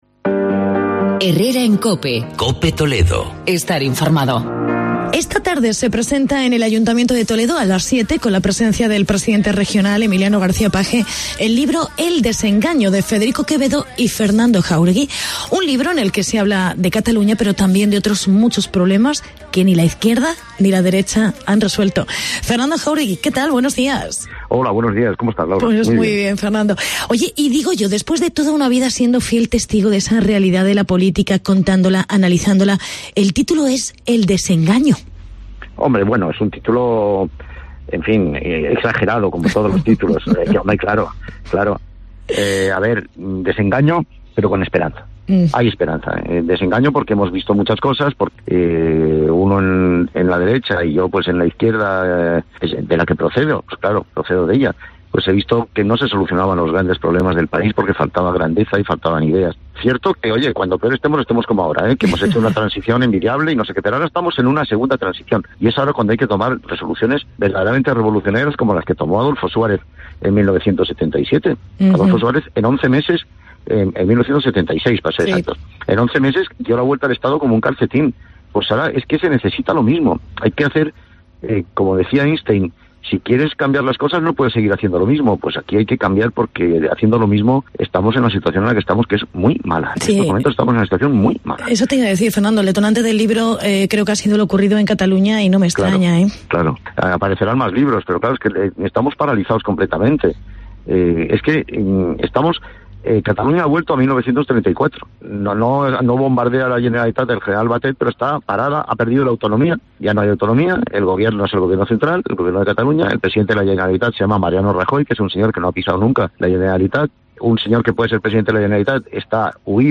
Entrevista al co-autor y periodista Fernando Jaúregui